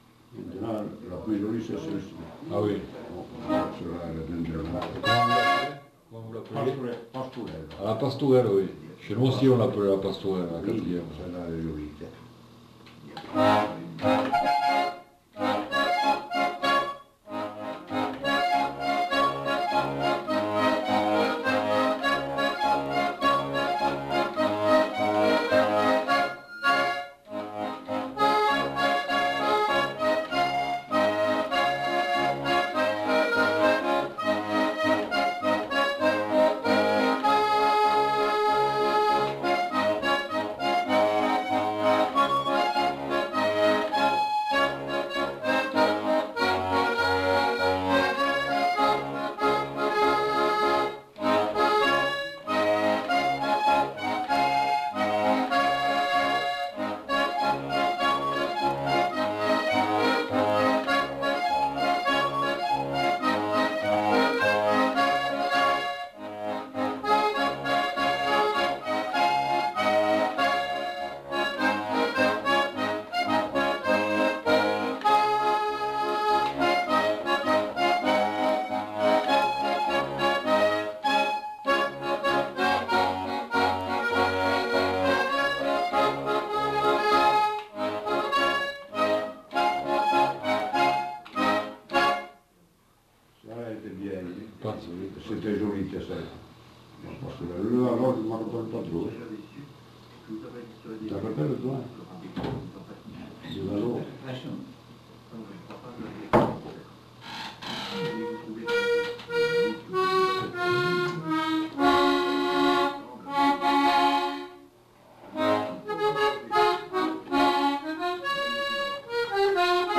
Aire culturelle : Bigorre
Lieu : Aulon
Genre : morceau instrumental
Instrument de musique : accordéon diatonique
Danse : quadrille (4e f.)